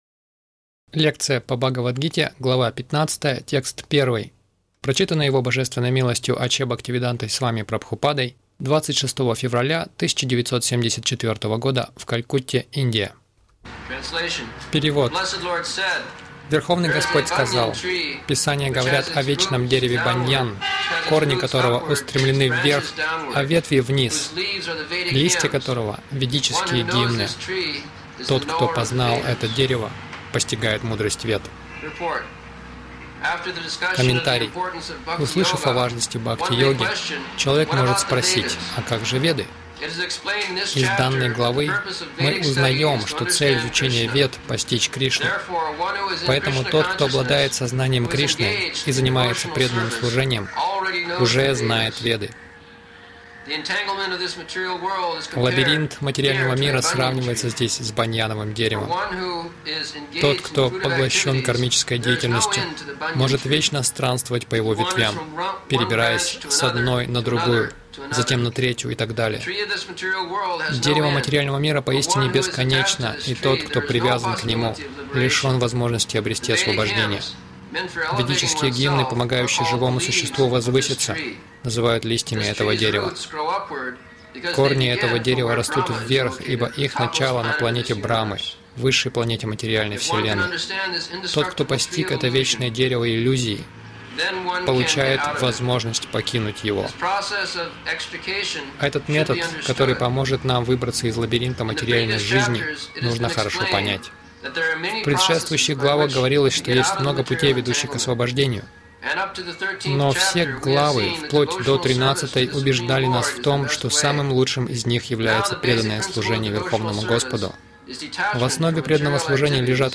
Милость Прабхупады Аудиолекции и книги 26.02.1974 Бхагавад Гита | Калькутта БГ 15.01 — Материальный мир — перевёрнутое дерево Загрузка...